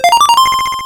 RedCoin4.wav